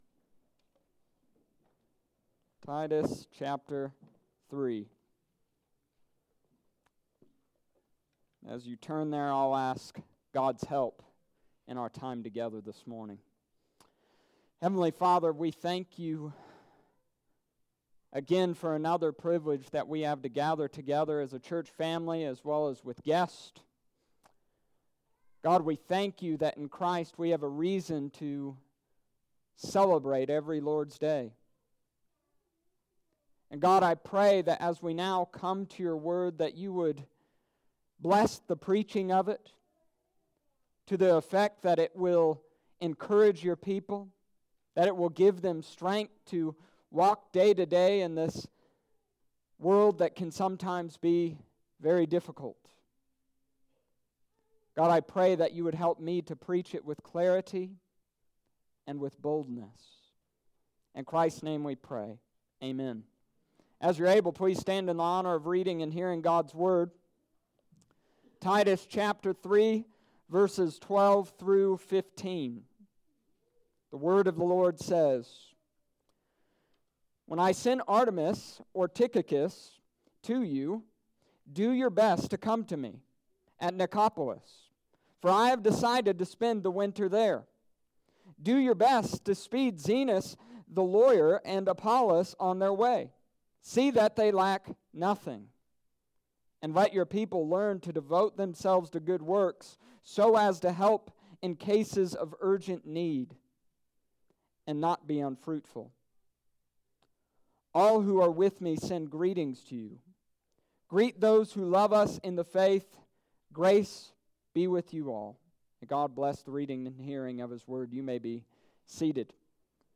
Summary of Sermon: This week, we continued the book of Titus.